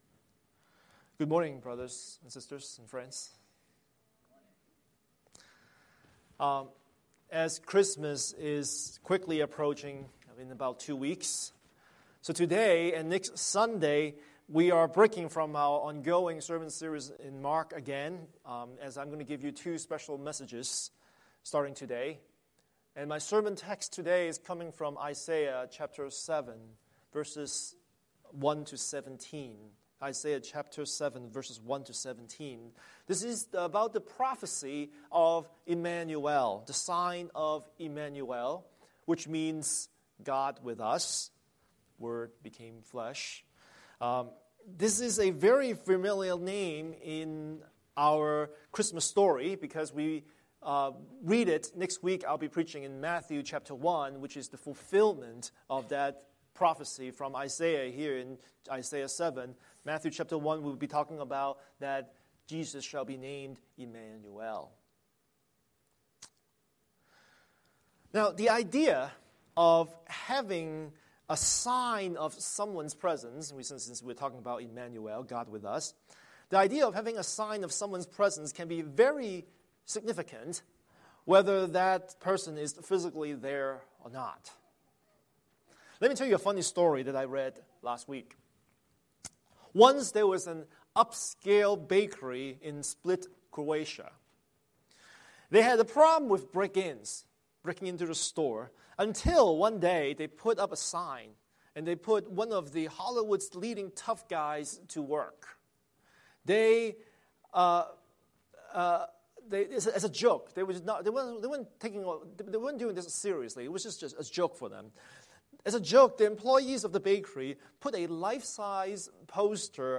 Scripture: Isaiah 7:1–17 Series: Sunday Sermon